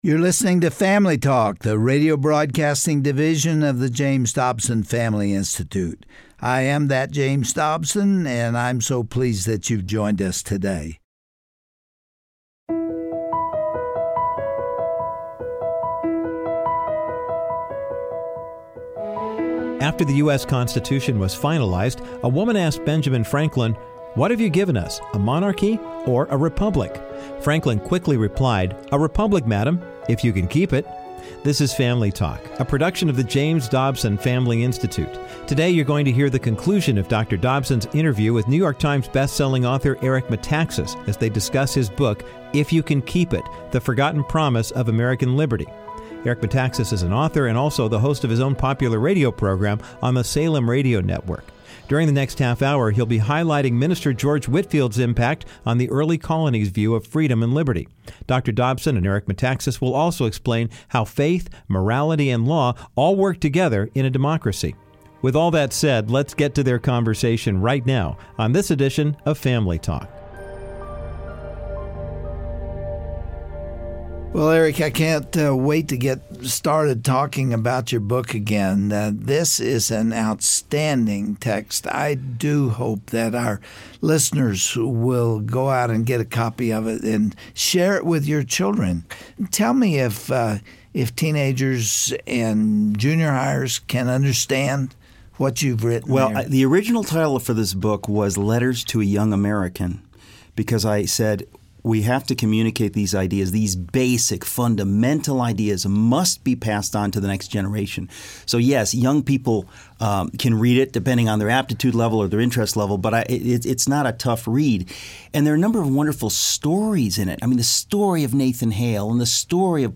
On today’s edition of Family Talk, Dr. James Dobson and Eric Metaxas continue to discuss the state of our country and Eric’s book, If You Can Keep It: The Forgotten Promise of American Liberty. Dr. Dobson and Metaxas remind us of the importance of American liberty, and what we can do as a nation to save it.